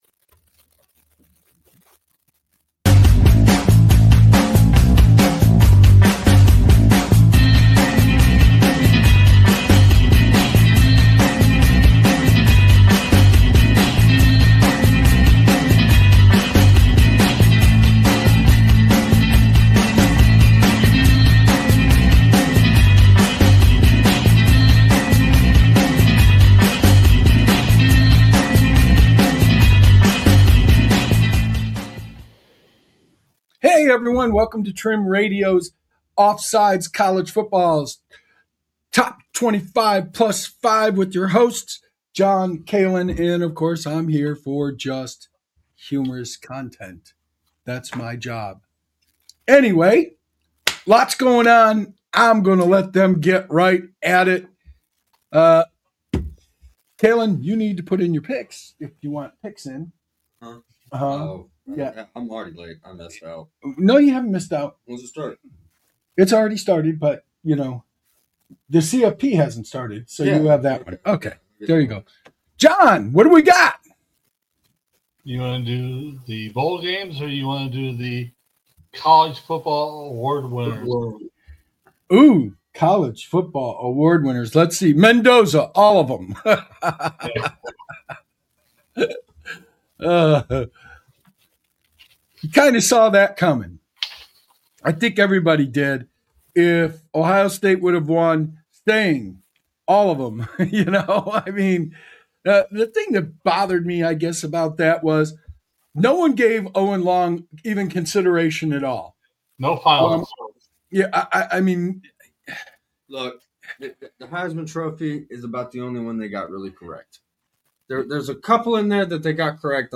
From the injustice served to Notre Dame to the puzzling inclusion of Alabama, the duo debates the need for a 16 or 32-team playoff expansion to restore meritocracy to the sport.